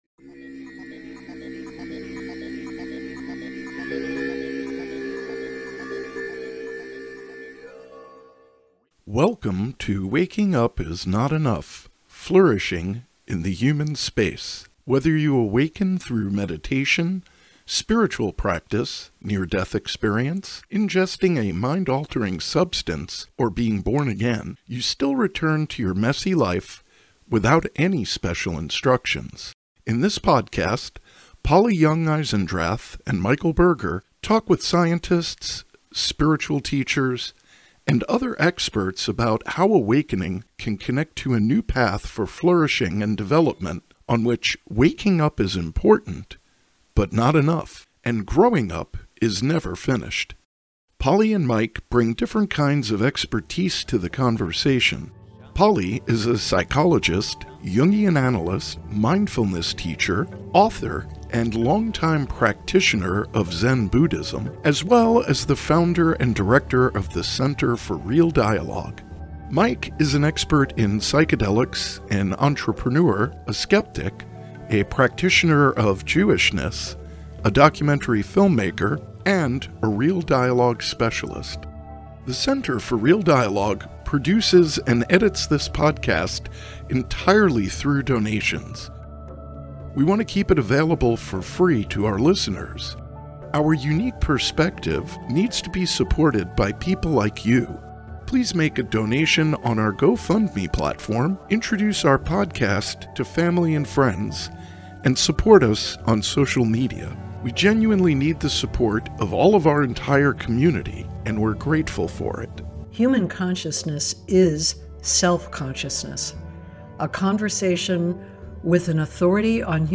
Waking Up is Not Enough Episode 31 - Human Consciousness is Self-Consciousness: A Conversation with an Authority on Human Consciousness